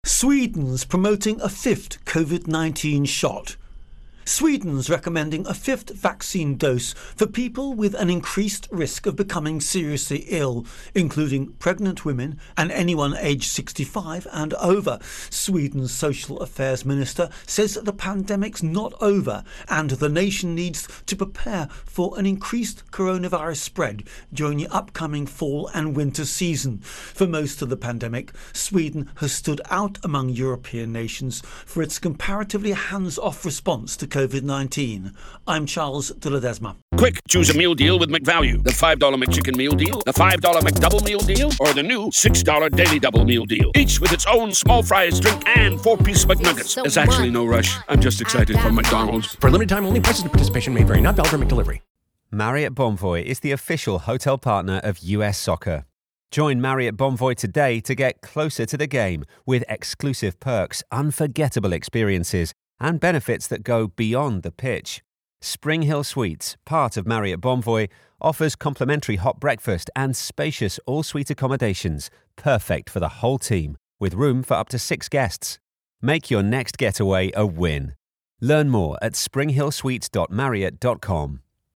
Virus Outbreak Sweden Intro an Voicer